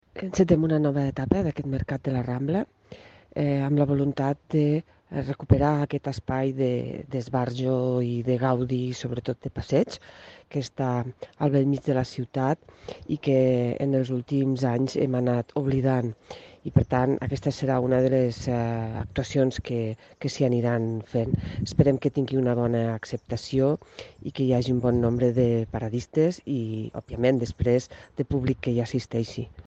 tall-de-veu-de-marta-gispert